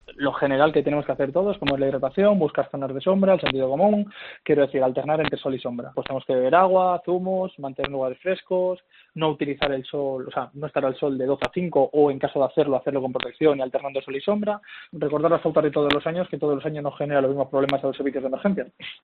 técnico de emergencias sanitarias